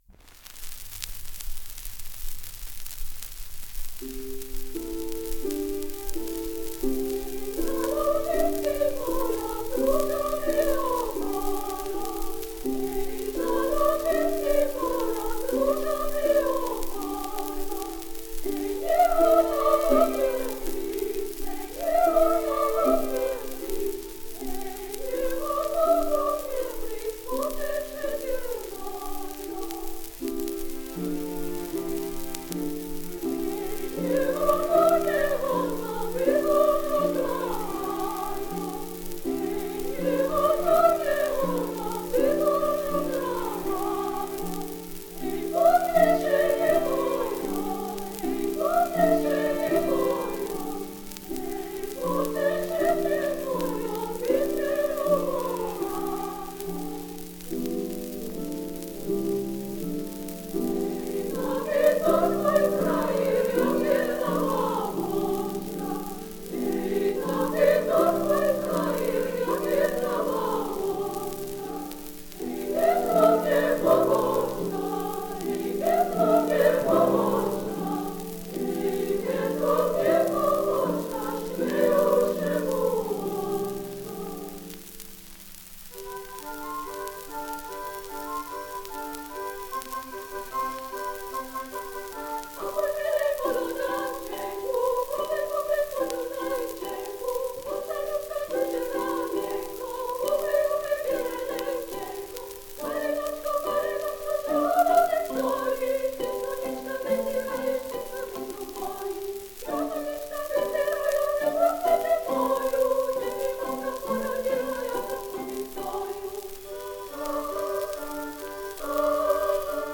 Ženský zborový spev so sprievodom orchestra.
slovenské ľudové piesne